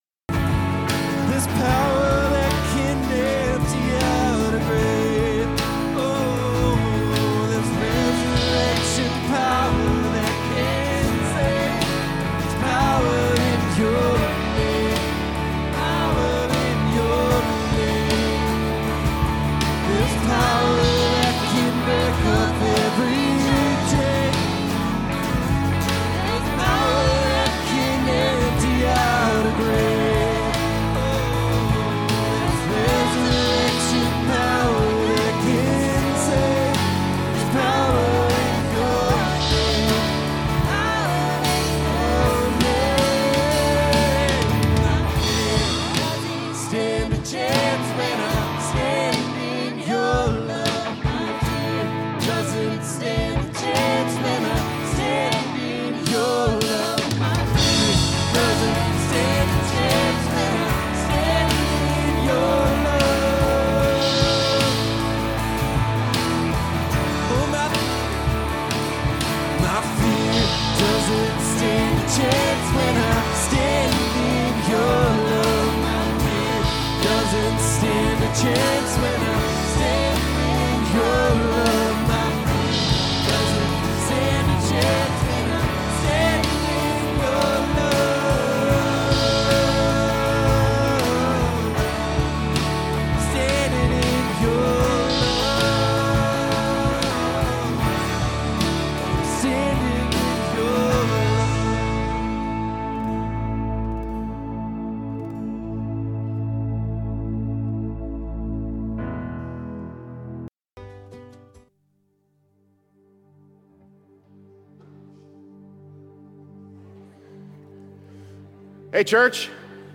Sermons by Renew Church